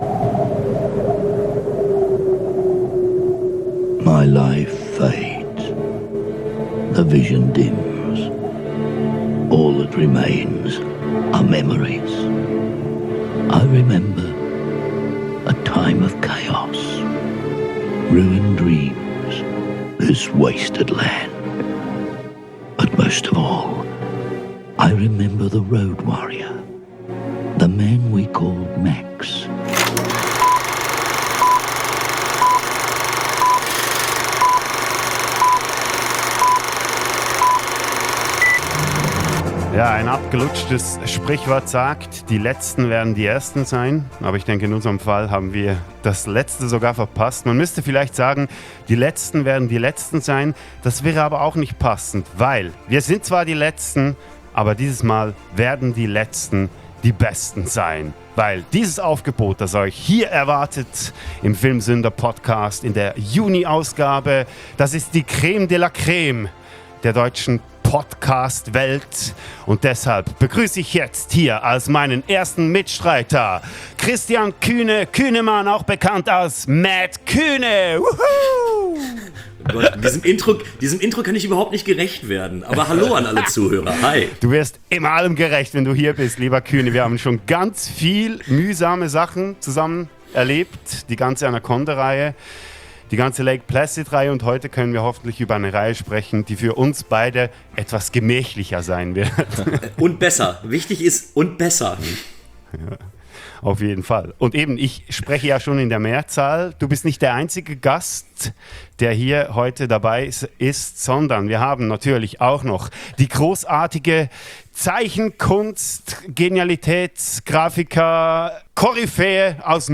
:-P Und auch sonst lässt die Tonqualität etwas zu wünschen übrig.